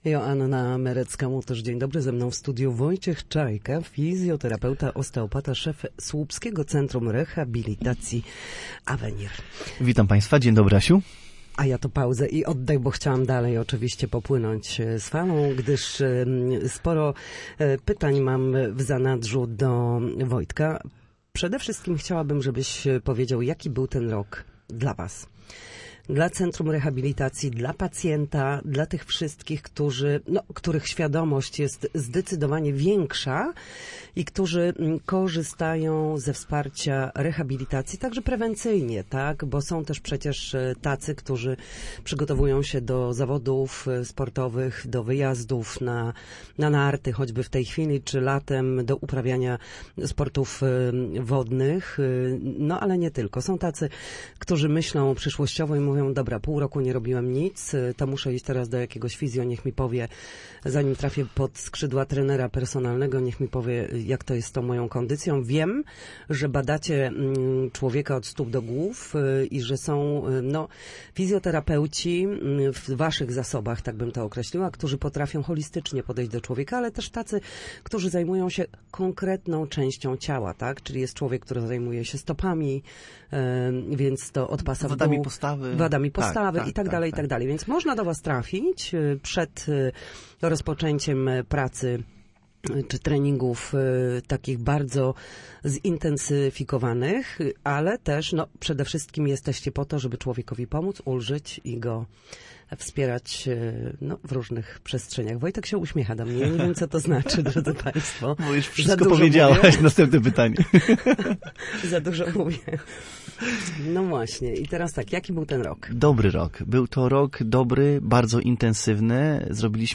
W każdą środę, w popołudniowym Studiu Słupsk Radia Gdańsk, dyskutujemy o tym, jak wrócić do formy po chorobach i urazach.